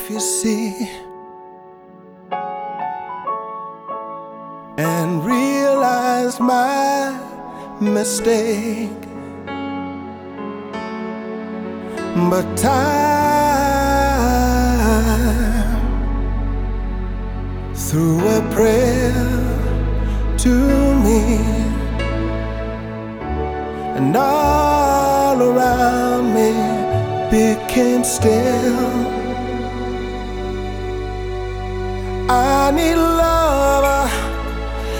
Жанр: Поп музыка / Рок / R&B / Танцевальные / Соул